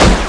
PUMPGUN
SHOTWALL2.WAV